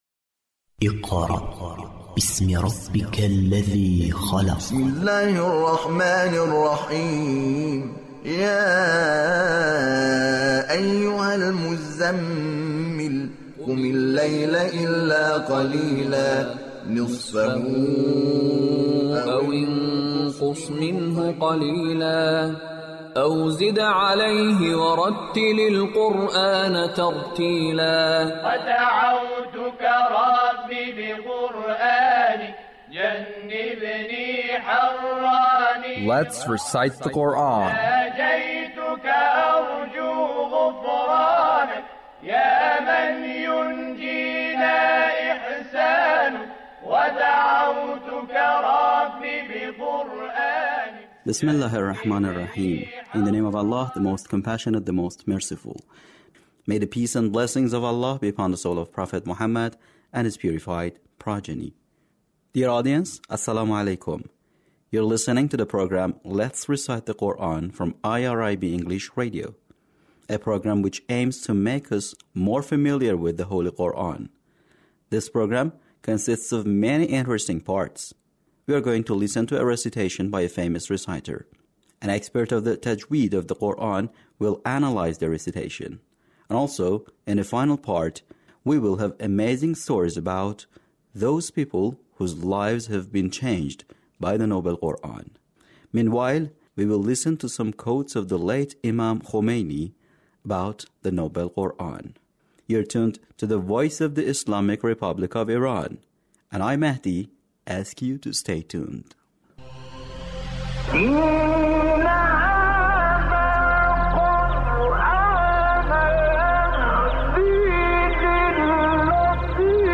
Recitation of surah al-Shura - Attractiveness of the Noble Quran